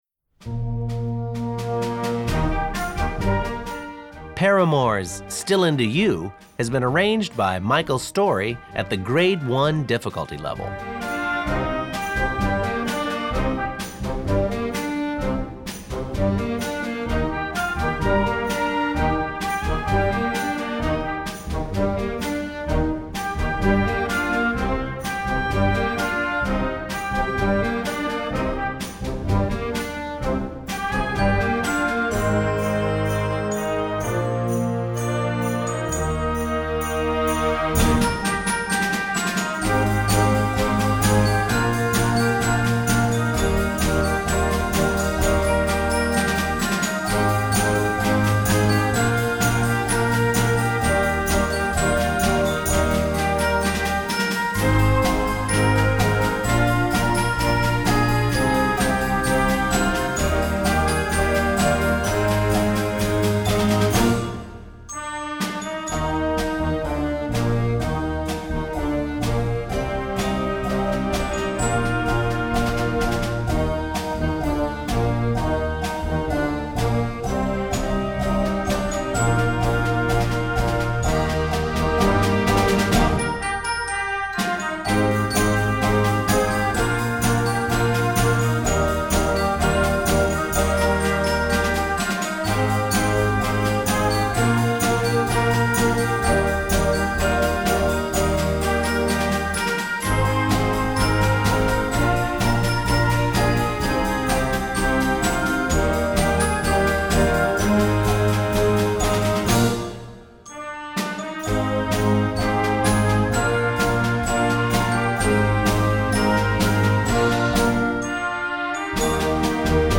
Besetzung: Blasorchester
This energetic hit